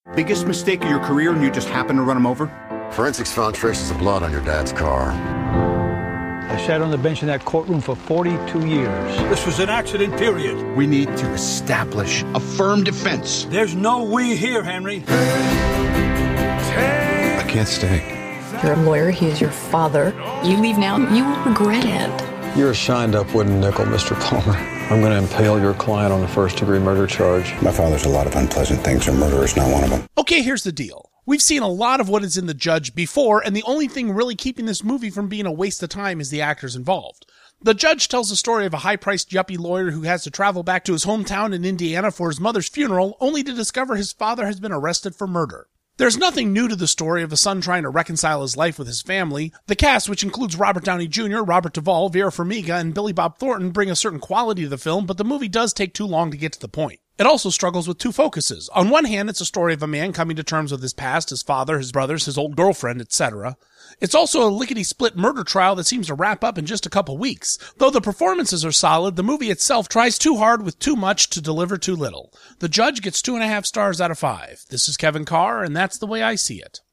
‘The Judge’ Movie Review